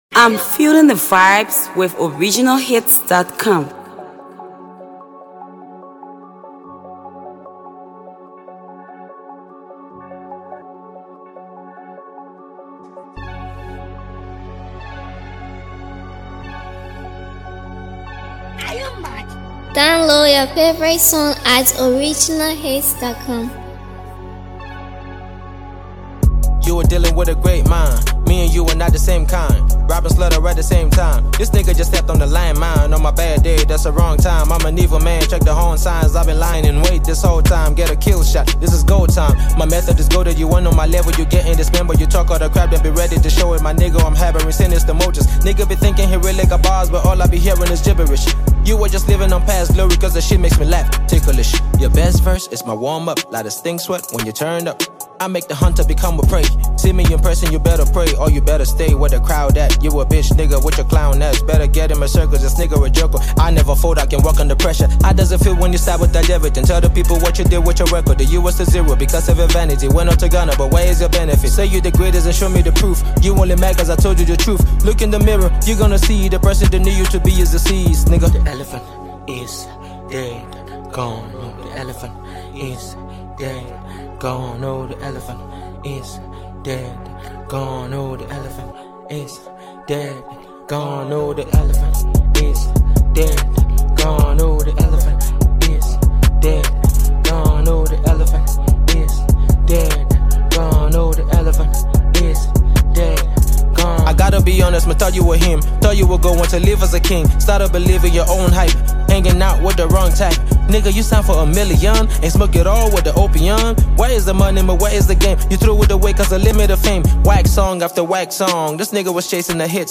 a diss track
HipHop